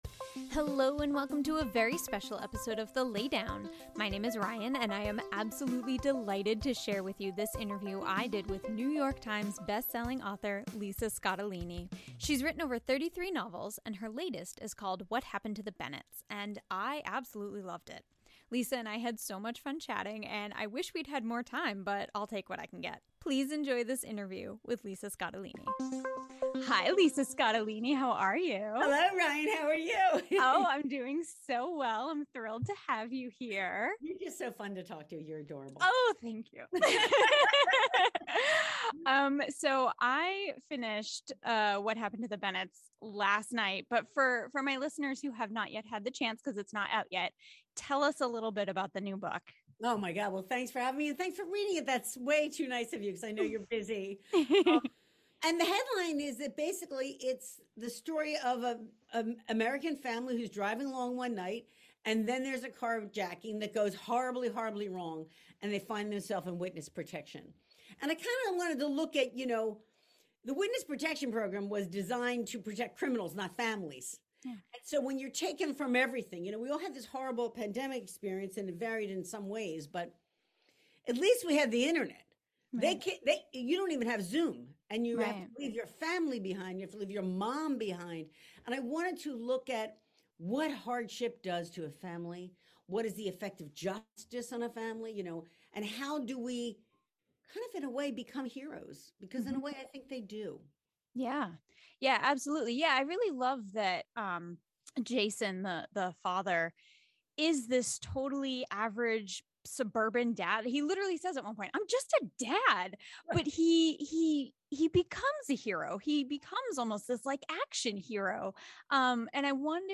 Lisa Scottoline Interview!